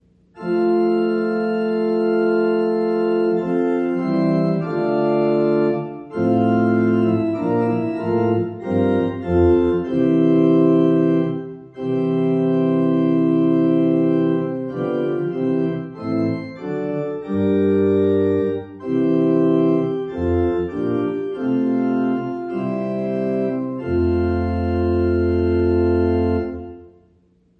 TLH Gloria Patri (F Major)